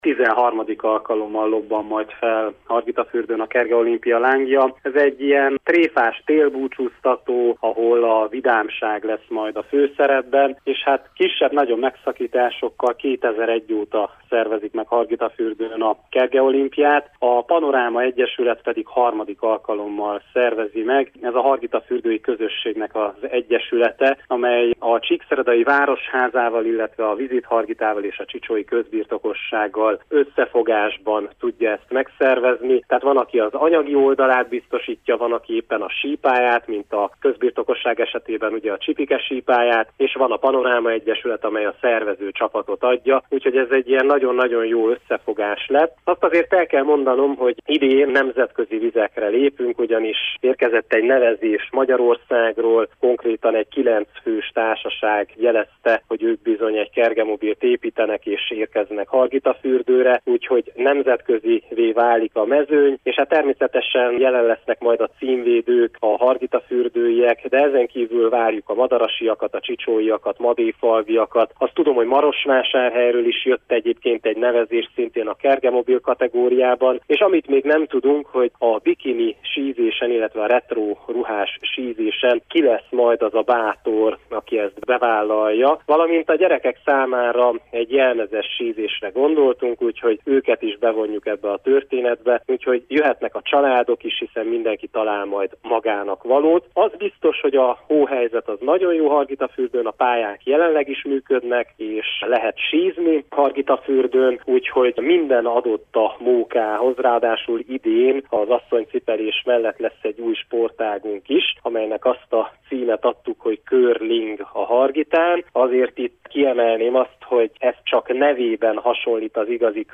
interjúja.